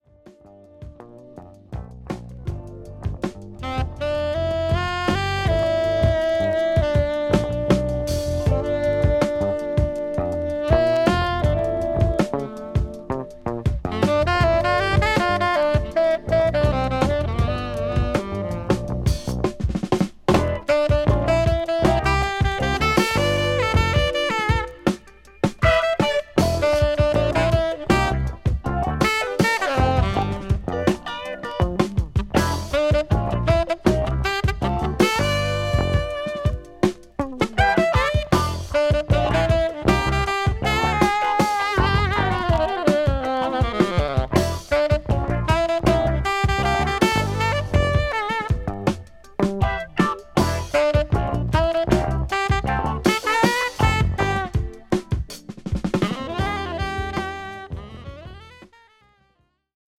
クロスオーバー/フュージョン色の濃い内容です